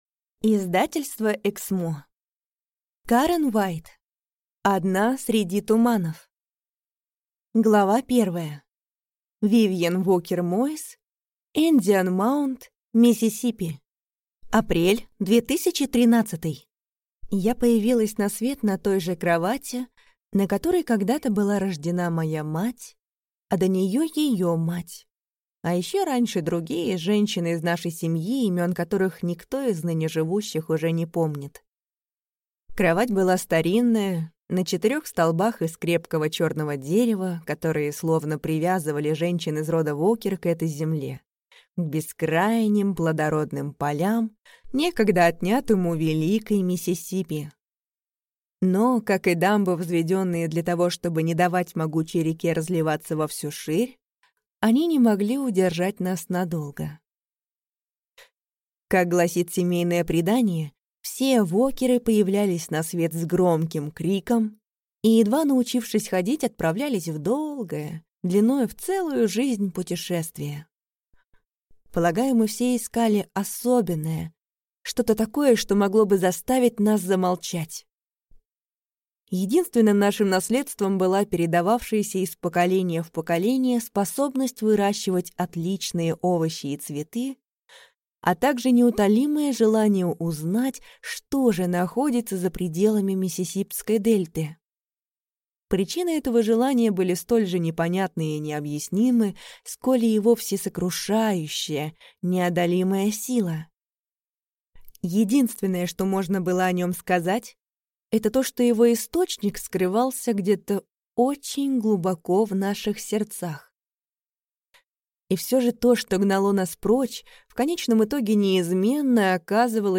Аудиокнига Одна среди туманов | Библиотека аудиокниг